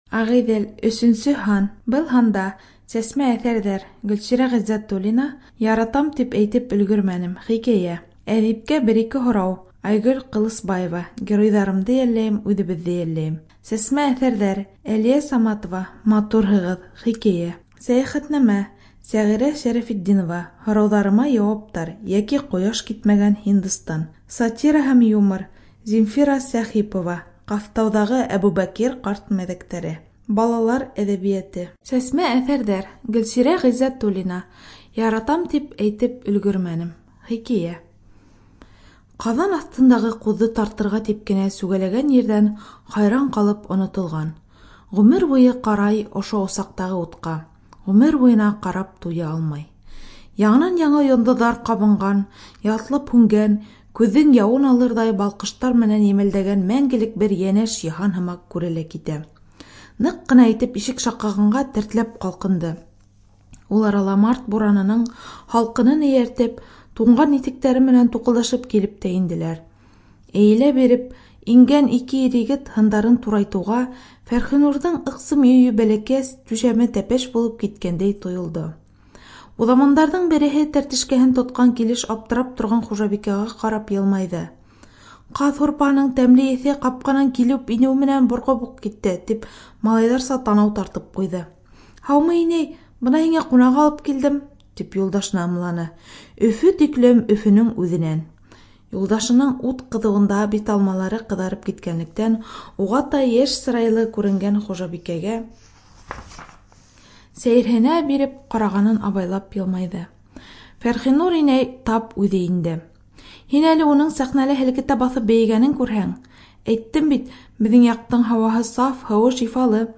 Студия звукозаписиБашкирская республиканская специальная библиотека для слепых